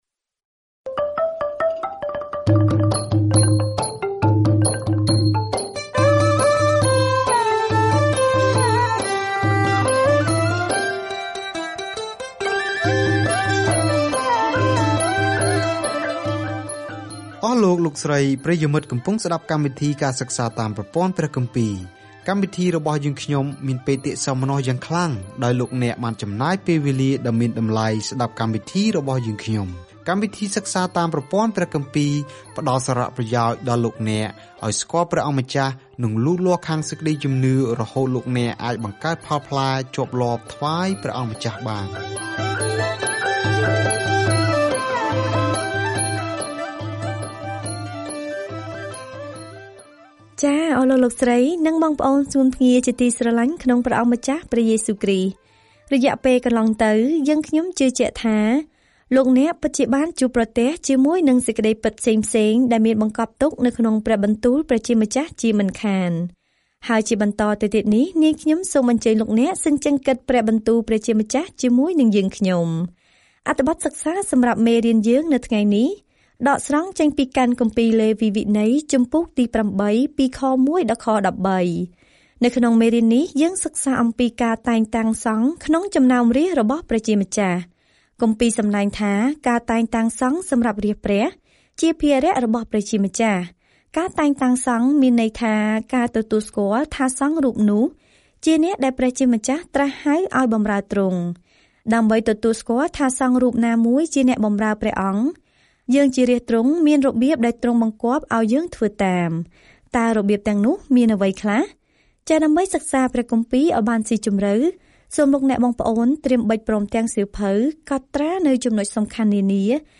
ការធ្វើដំណើរជារៀងរាល់ថ្ងៃតាមរយៈលេវីវិន័យ នៅពេលអ្នកស្តាប់ការសិក្សាជាសំឡេង ហើយអានខគម្ពីរដែលជ្រើសរើសពីព្រះបន្ទូលរបស់ព្រះ។